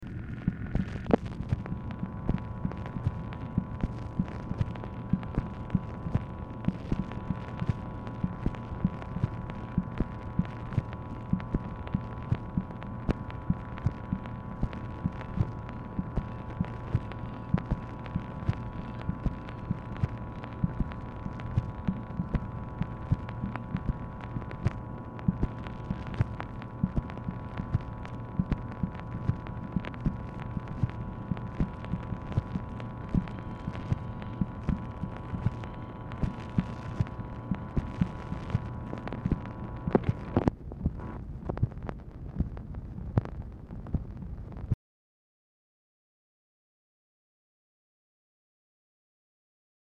Telephone conversation # 9201, sound recording, MACHINE NOISE, 11/30/1965, time unknown | Discover LBJ
Telephone conversation
Format Dictation belt
LBJ Ranch, near Stonewall, Texas